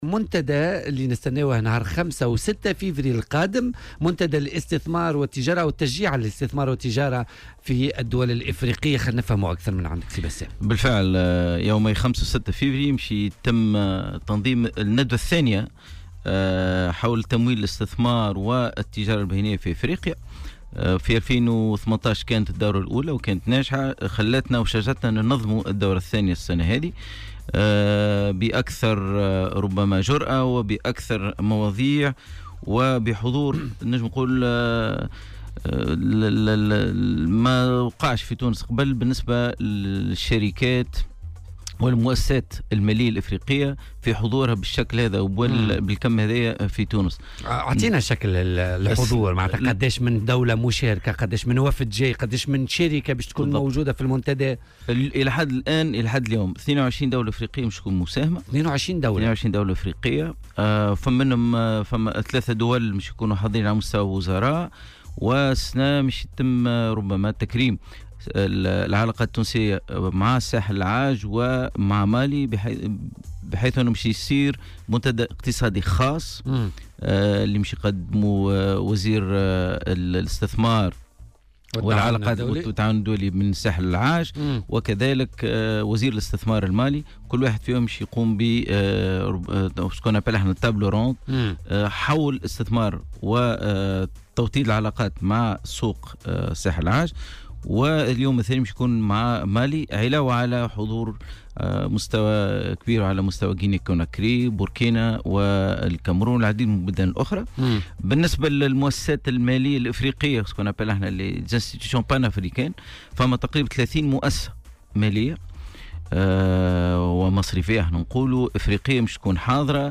في مداخلة له اليوم الثلاثاء في برنامج "بوليتيكا".